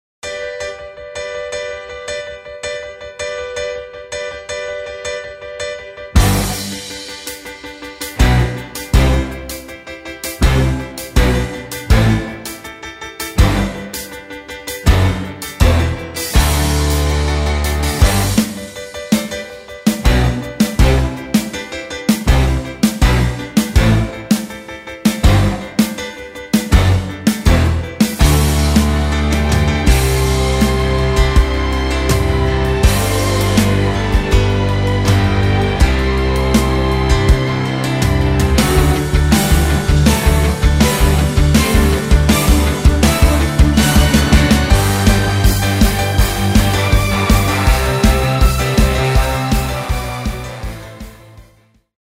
performance track
Musical
Instrumental , orchestral , backing track